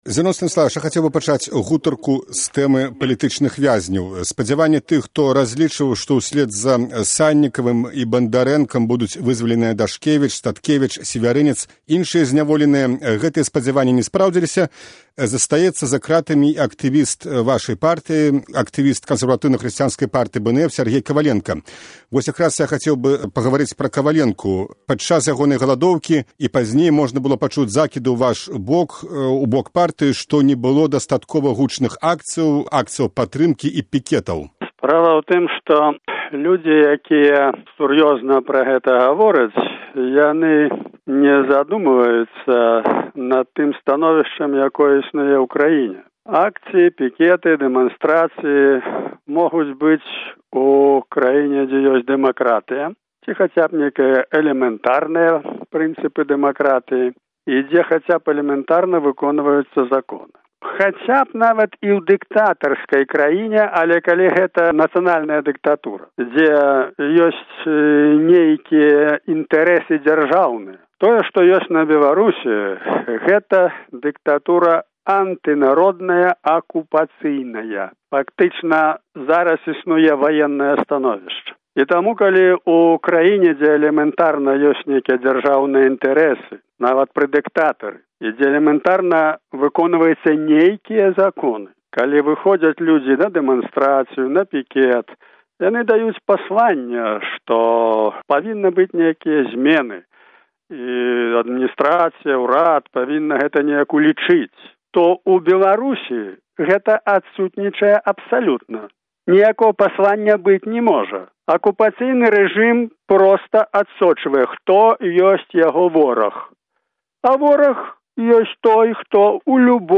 Госьць перадачы «Вынікі дня» – старшыня КХП-БНФ Зянон Пазьняк.
Гутарка зь Зянонам Пазьняком, 29 чэрвеня 2012 году